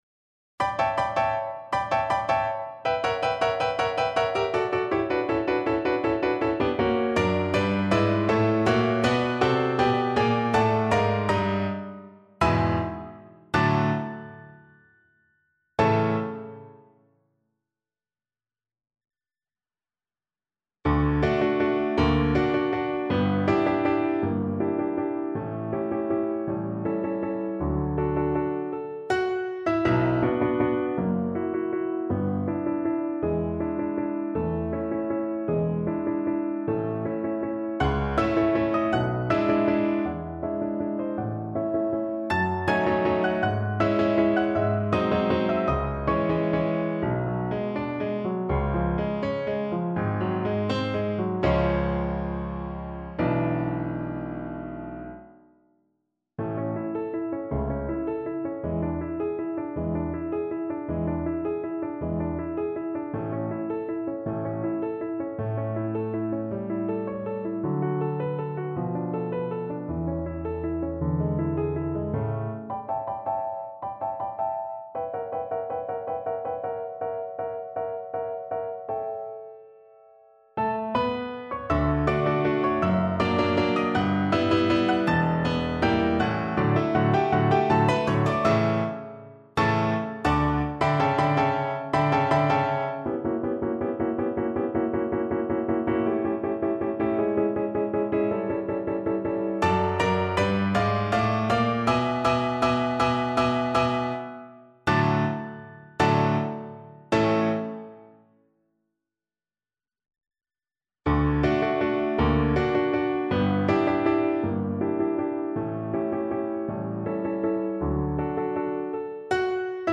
Andante animato
6/8 (View more 6/8 Music)
Classical (View more Classical Violin Music)